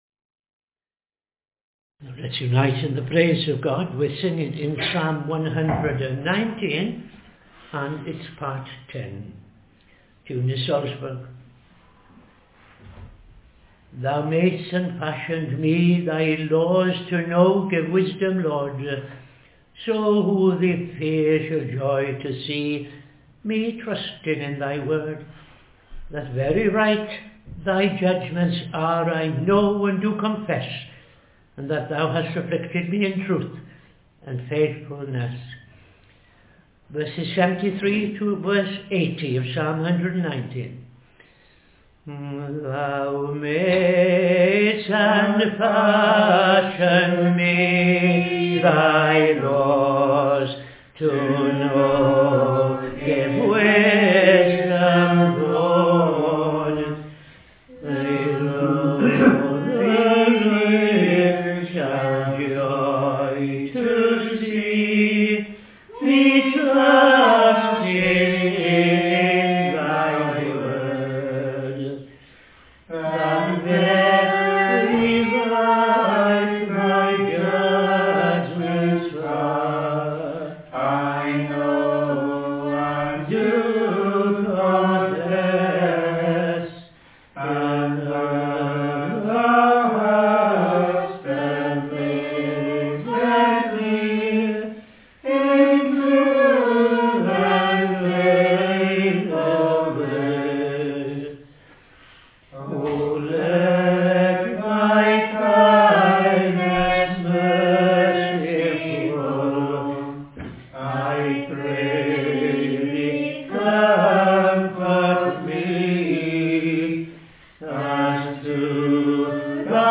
5.00 pm Evening Service Opening Prayer and O.T. Reading I Chronicles 18:1-17
Psalm 5:8-12 ‘Because of those mine enemies’ Tune Strathcaro